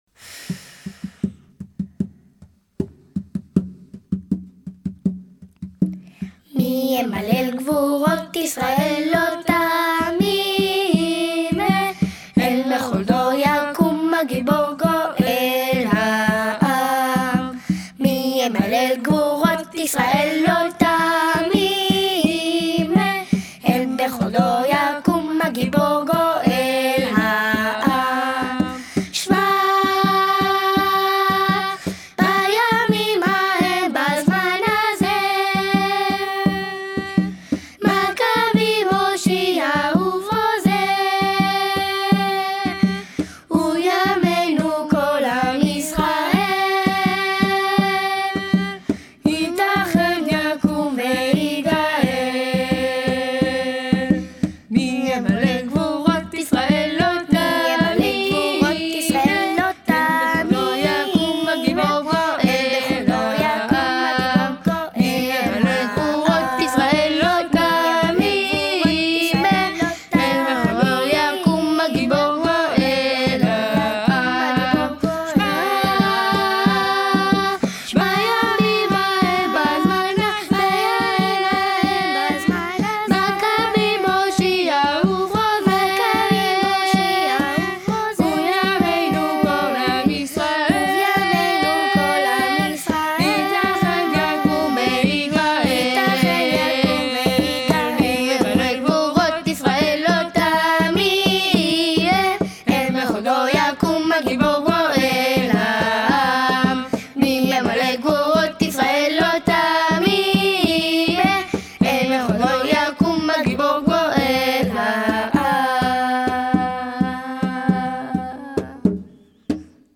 « Mi Yemalel » est une chanson très connue de 'Hanouka.
Audio Enfants: